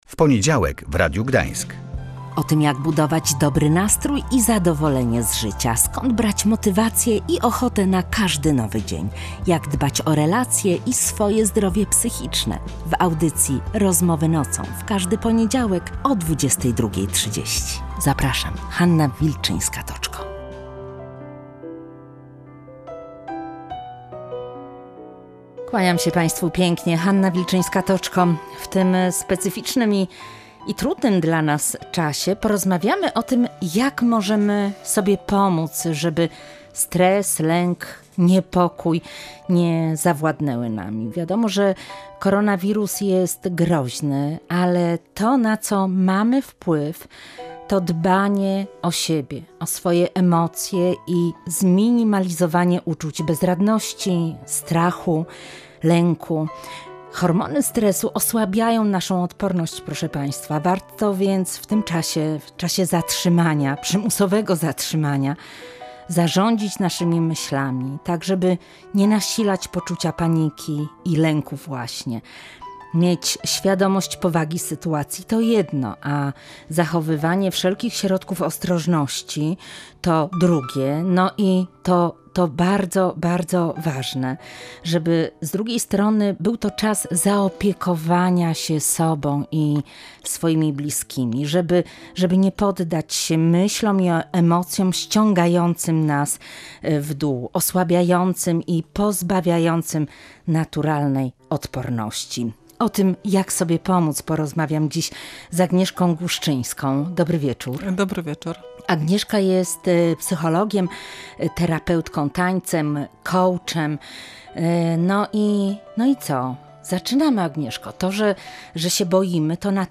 Jak sobie z tym poradzić? Odpowiada psycholożka